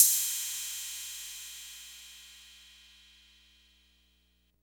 Index of /90_sSampleCDs/Roland - Rhythm Section/DRM_Drum Machine/KIT_TR-808 Kit
CYM 808 CY09.wav